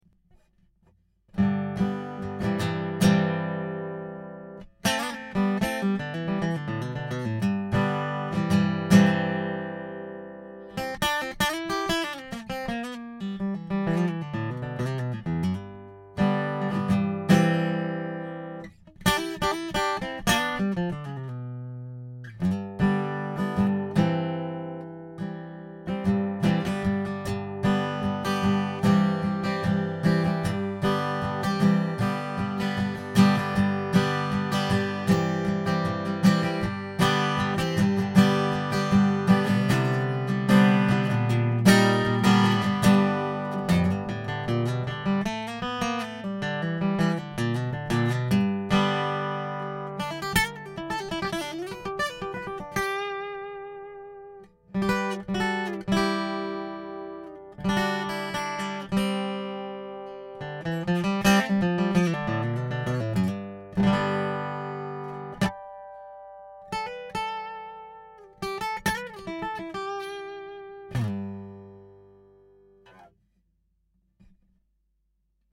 Acoustic Guitar (Gibson J-185)
A Gibson J-185 directly recorded through the DI02.